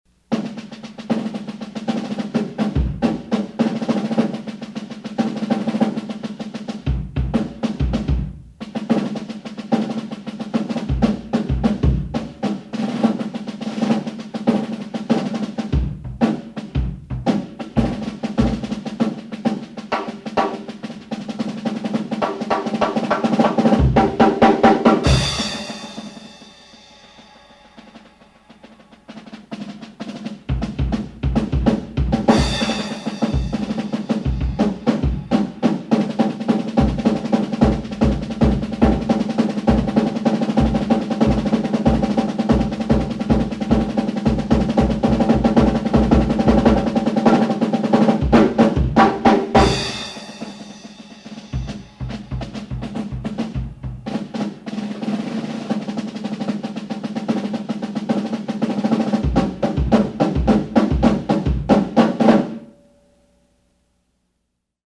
snare drum, bass drum with pedal, cymbals of your choice